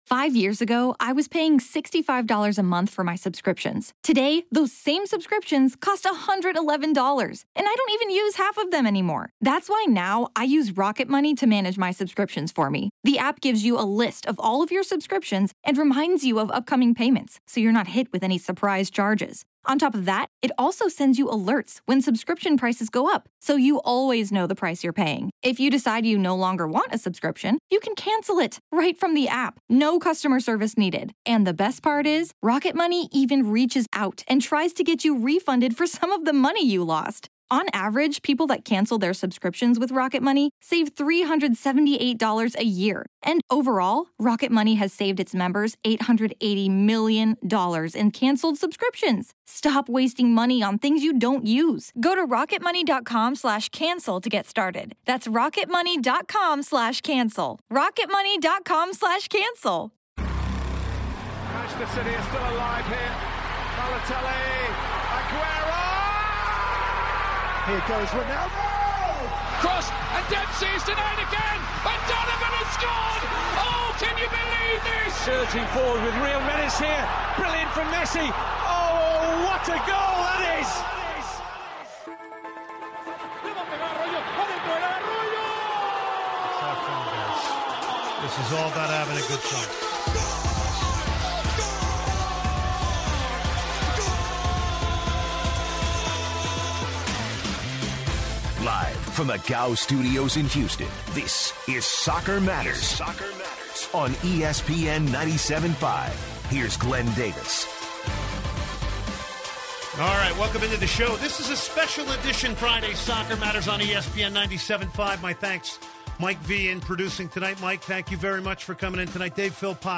Today's edition of soccer matters features two special guests!